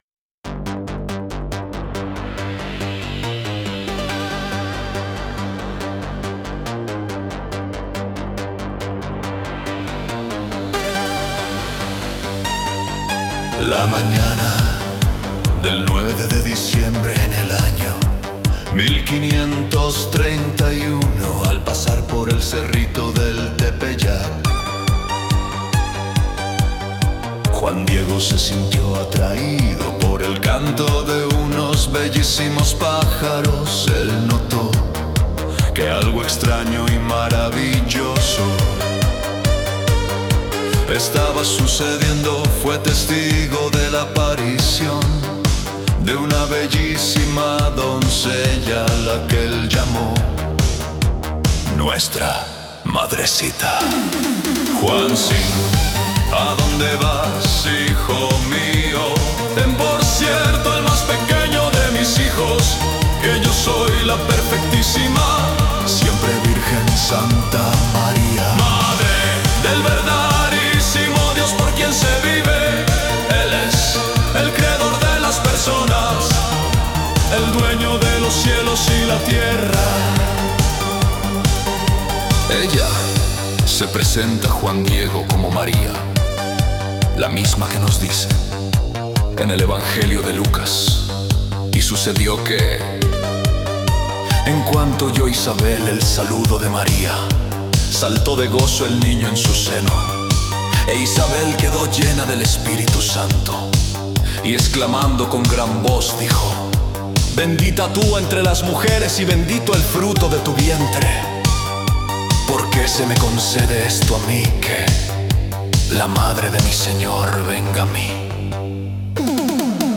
Escucha Música Muestra 6: alternativa gótico